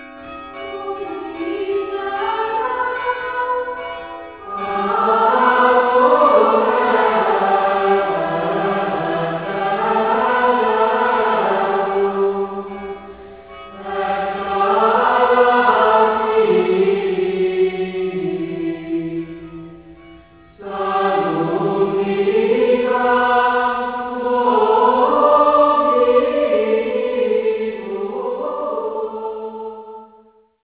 Gregorián énekekkel:1.Evening